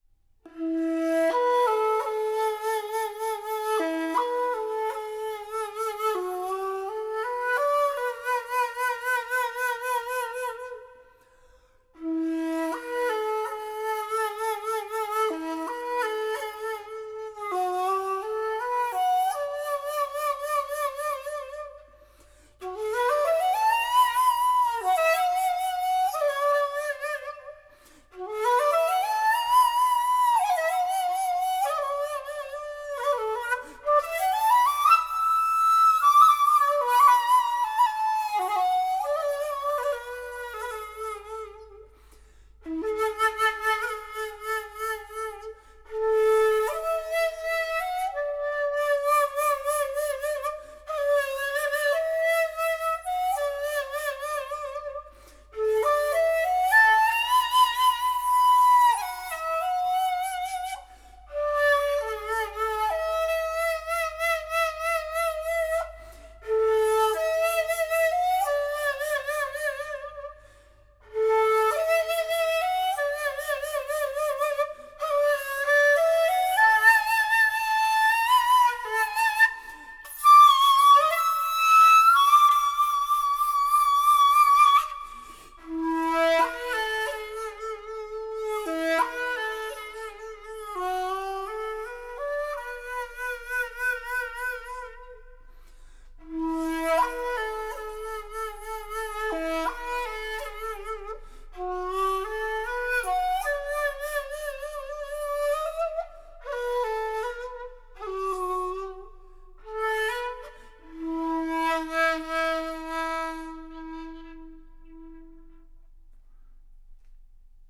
Shaku-Hatchi